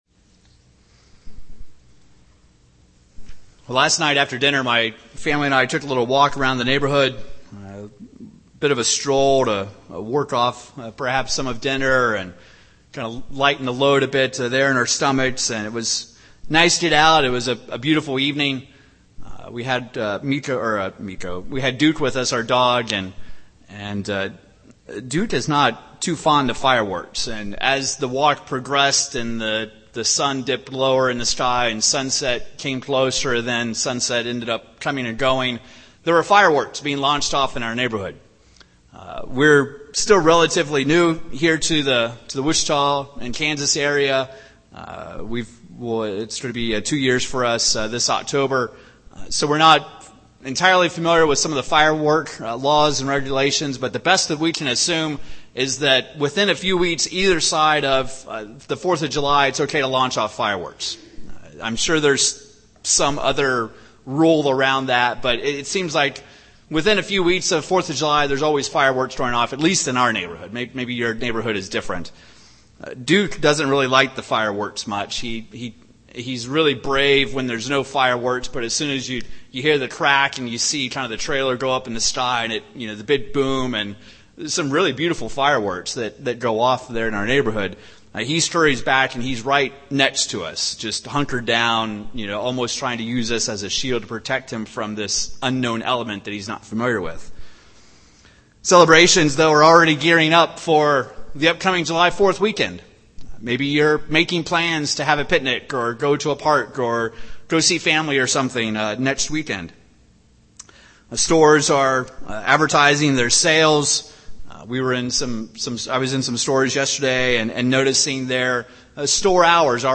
Given in Wichita, KS
UCG Sermon Studying the bible?